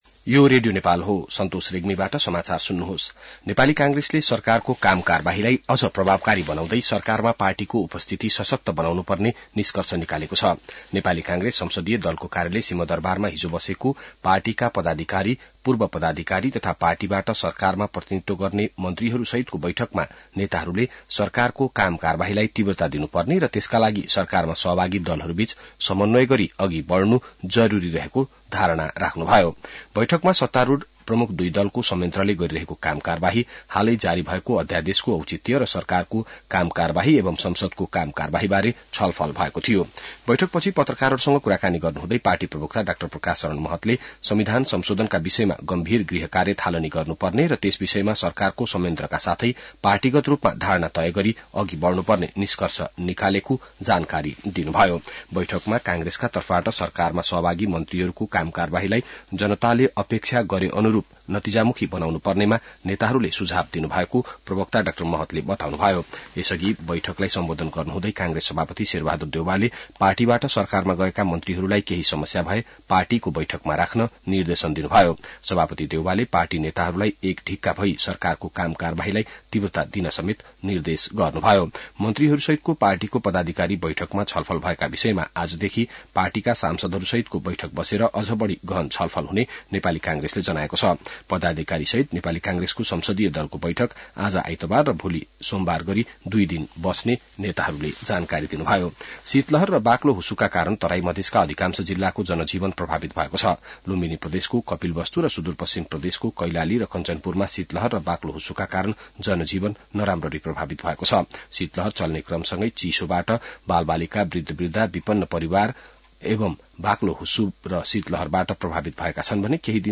बिहान ६ बजेको नेपाली समाचार : १४ माघ , २०८१